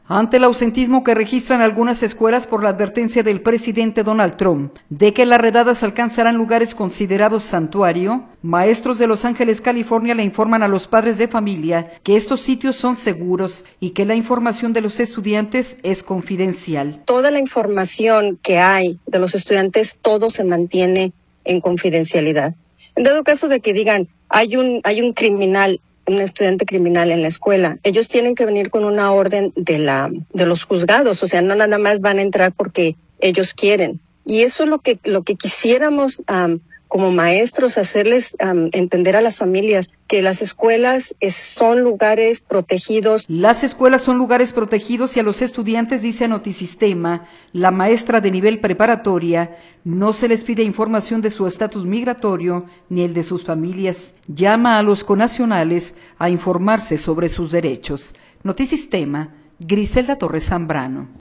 Las escuelas son lugares protegidos y a los estudiantes, dice a Notisistema la maestra de nivel preparatoria, no se les pide información de su estatus migratorio ni el de sus familias. Llama a los connacionales a informarse sobre sus derechos.